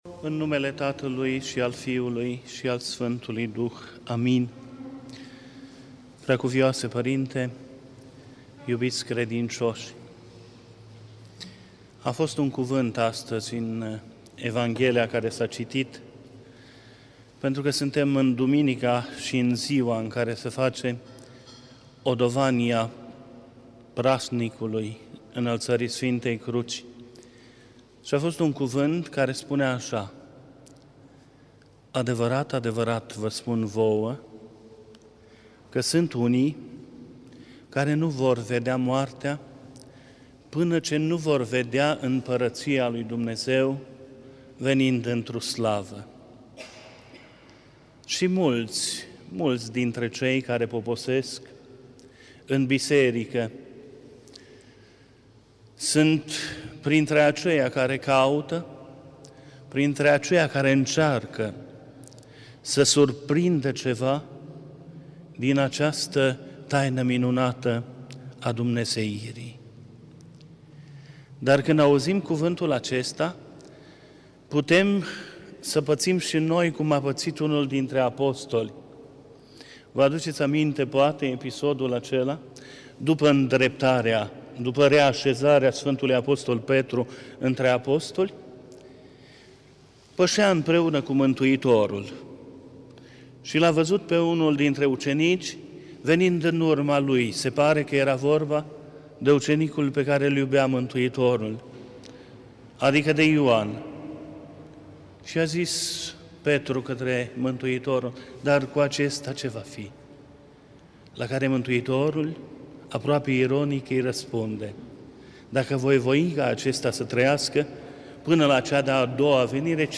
Cuvinte de învățătură Predică la Duminica după Înalțarea Sfintei Cruci